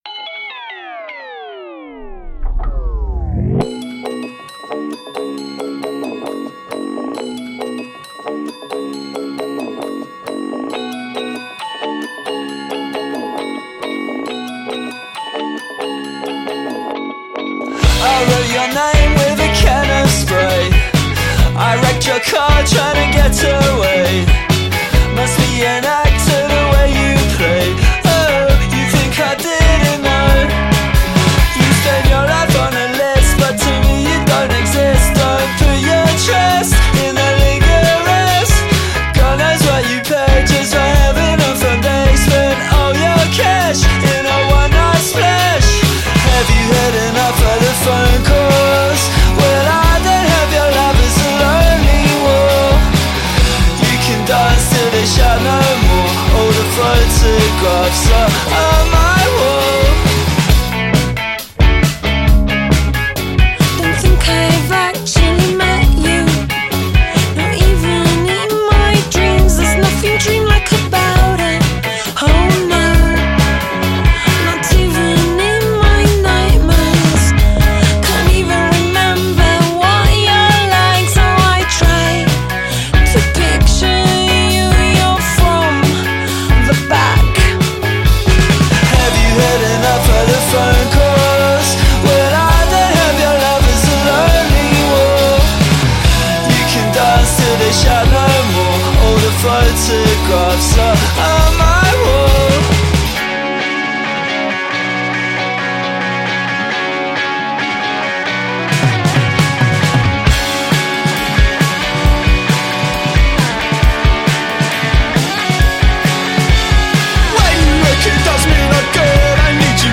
плачущий лондонский брит поп